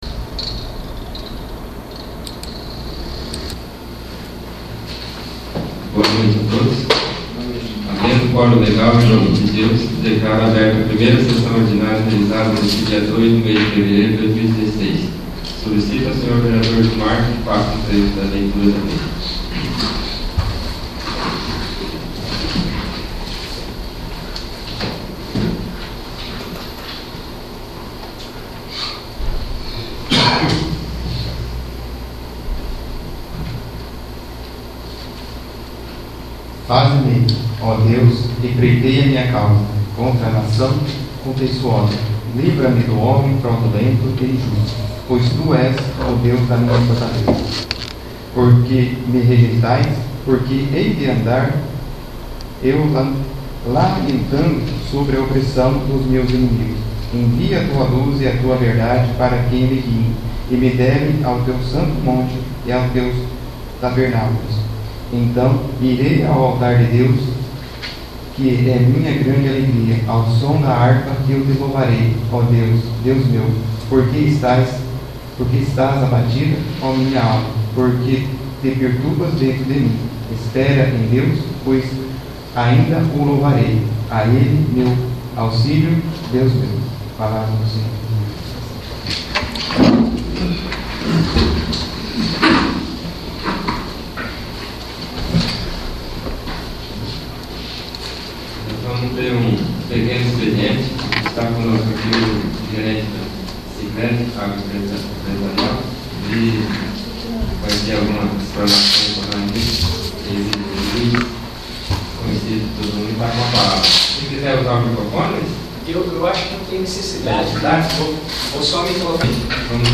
1º. Sessão Ordinária